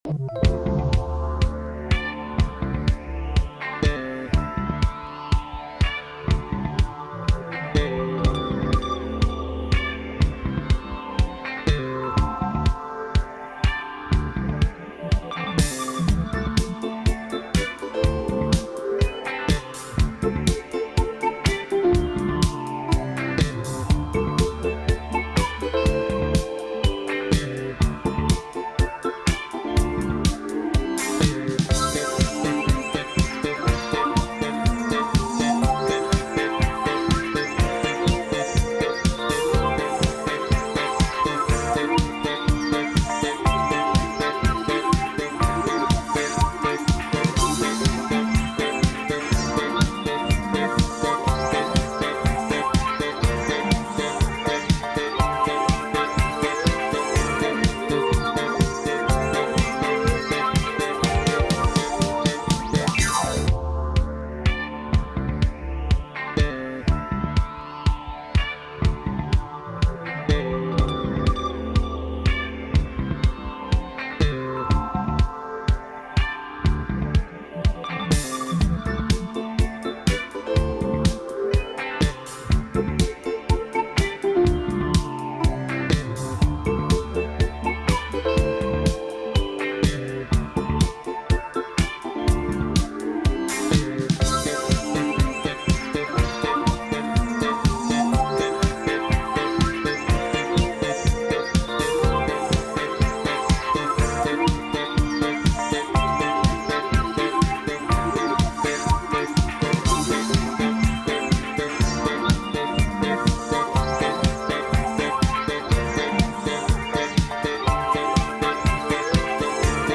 Bande originale 33t.